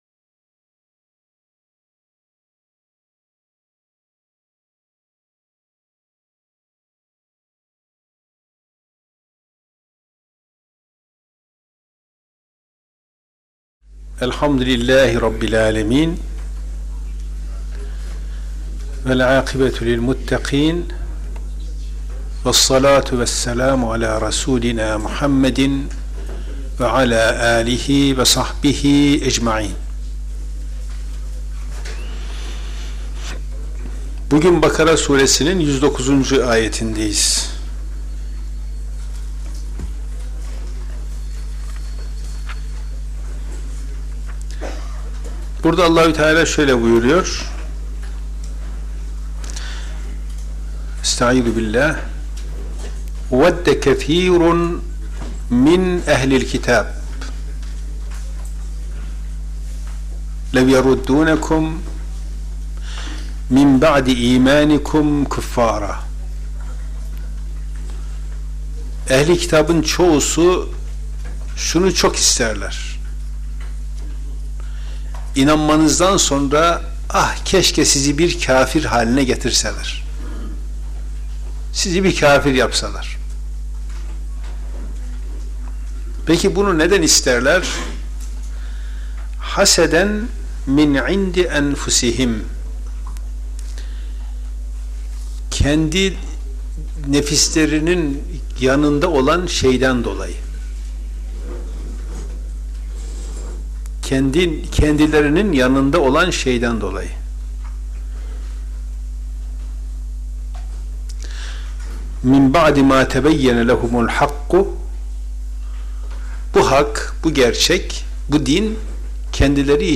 Kur'an Sohbetleri